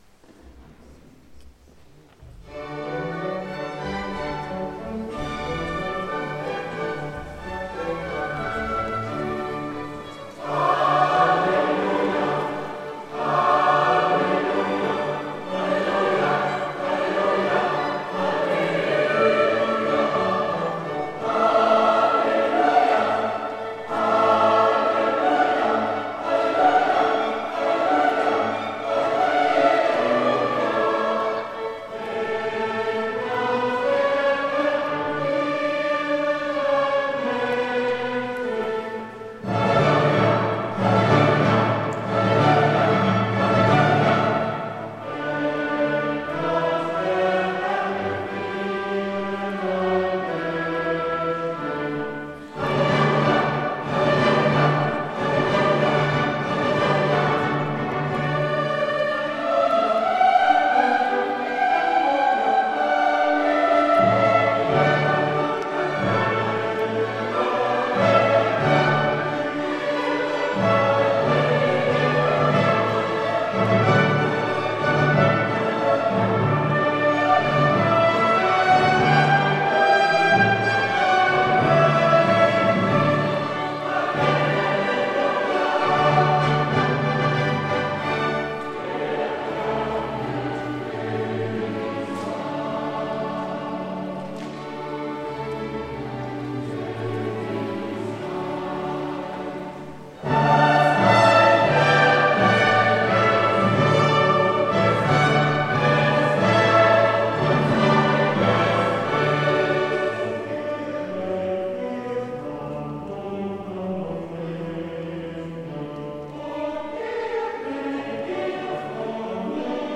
(Ostern)
Franz Schubert (1797 – 1828) Messe in B, D 324 – op. post. 141 für Chor, Soli und Orchester